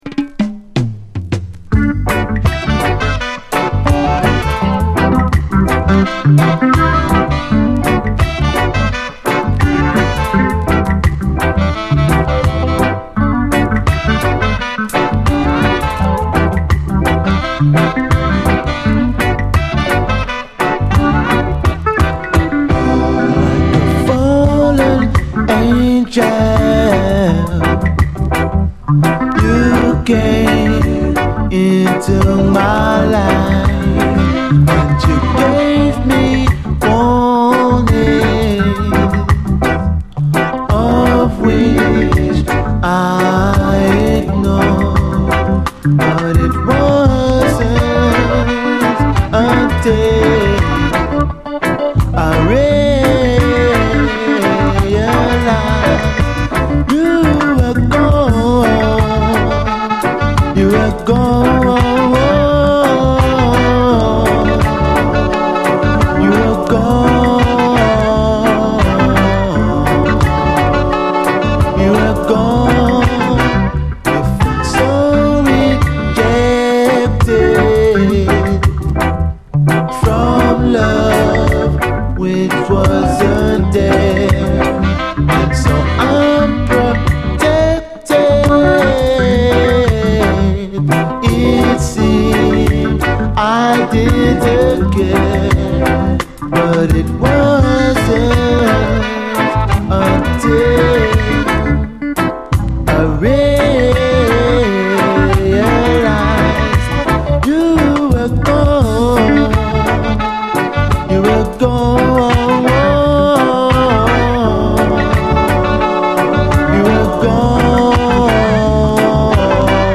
REGGAE
まどろむようにまろやかな歌声＆メロディー